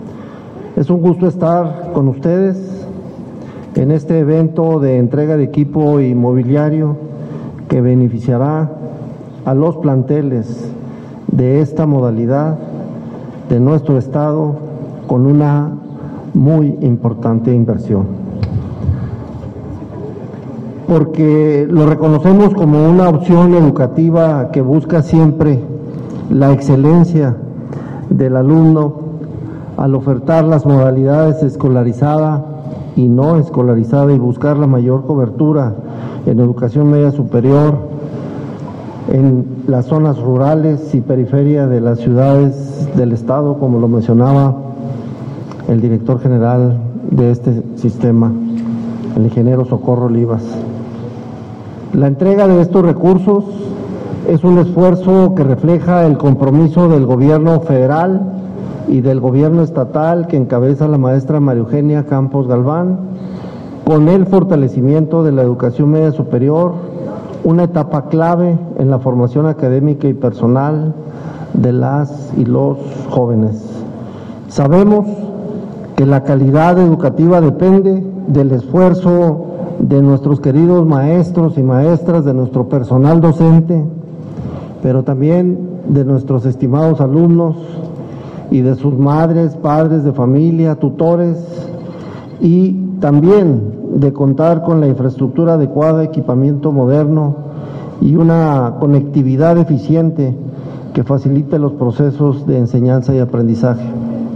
audio-secretario_de_educacion_y_deporte-entrega_de_equipamiento.mp3